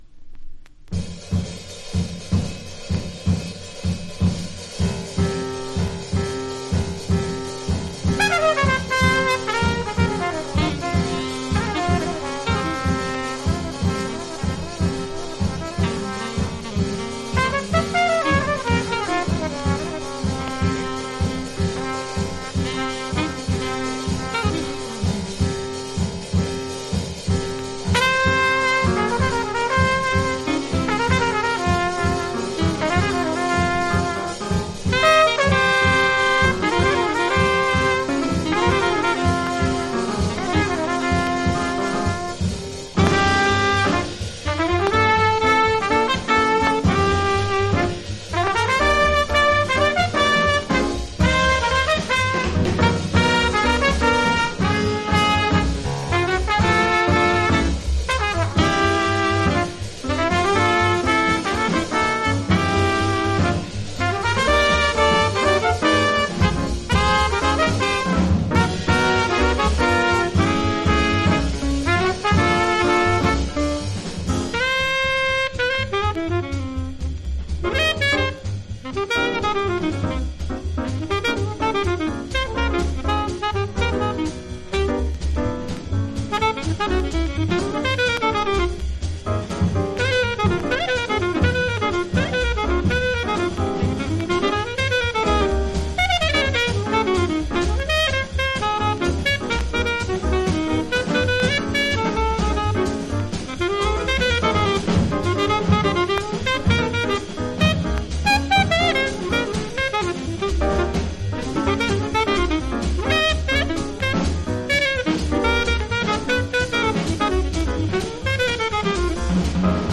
（小傷によりチリ、プチ音ある…